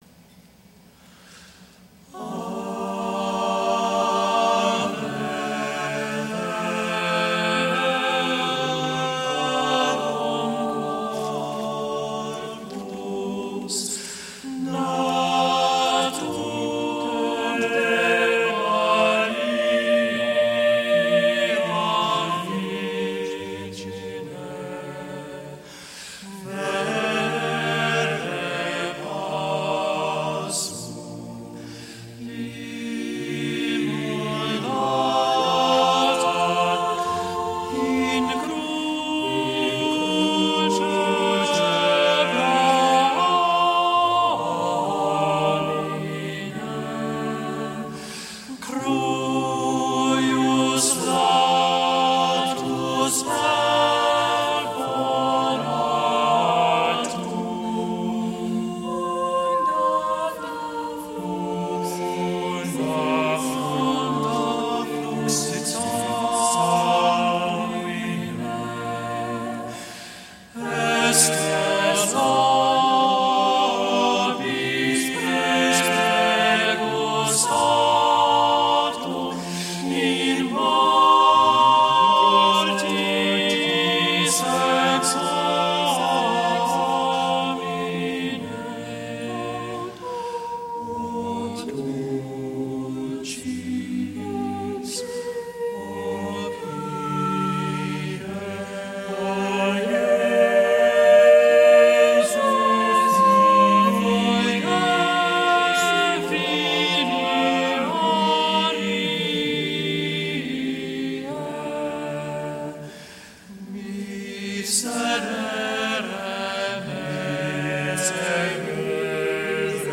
Recorded at the Renovaré International Conference in Denver, CO.